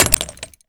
grenade_hit_06.WAV